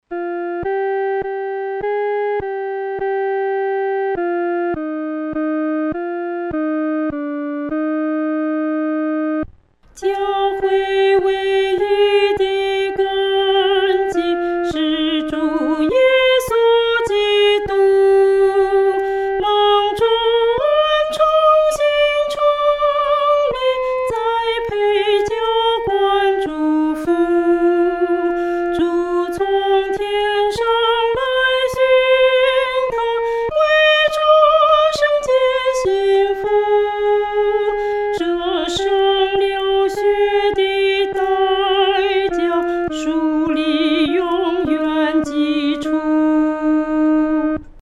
独唱（第一声）
教会维一的根基-独唱（第一声）.mp3